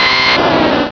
Cri de Florizarre dans Pokémon Rubis et Saphir.